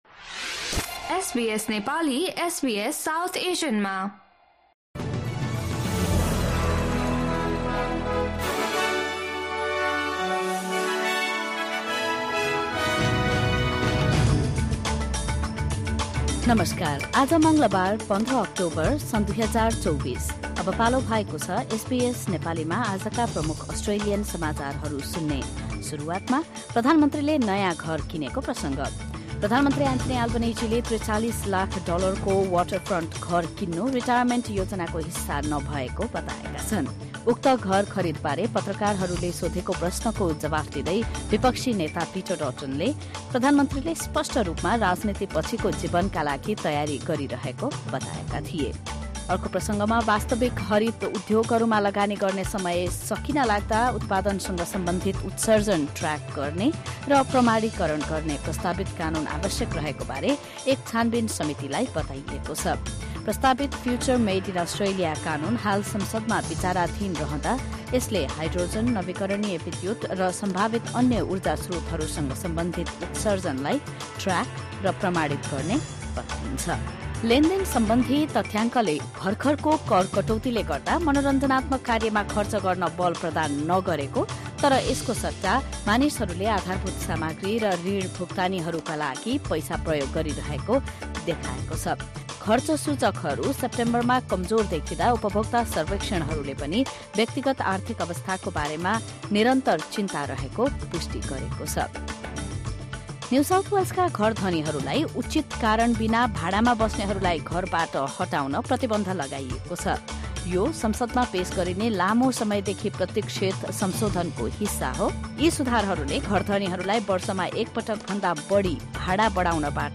SBS Nepali Australian News Headlines: Tuesday, 15 October 2024